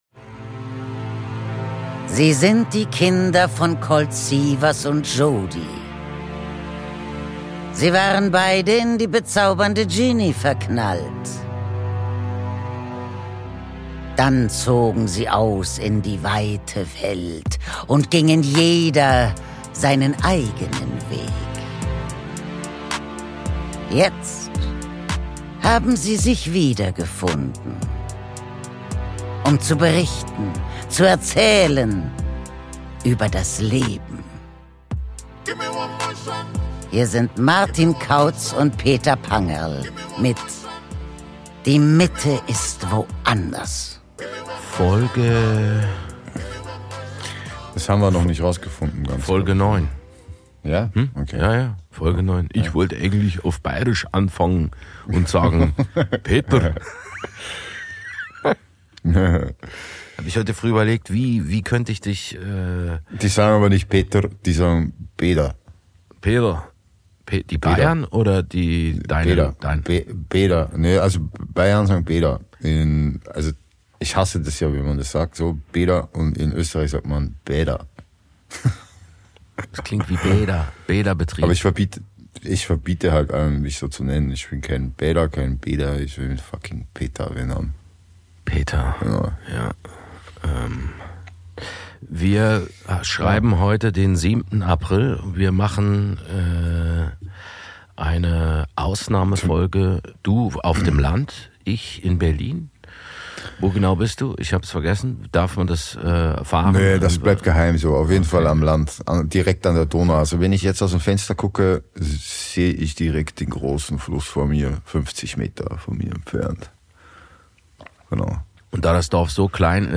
Aus dem 25h Hotel im Wiener Museumsquartier und Berlin und vom Land